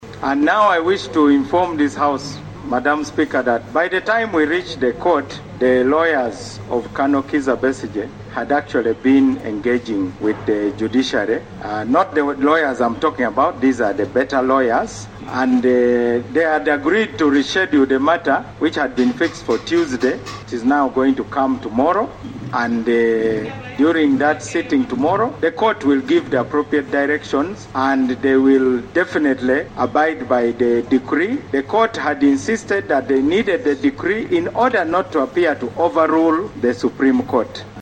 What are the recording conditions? The continued detention and health condition of Besigye was a centre of debate during the plenary on 18 February 2025.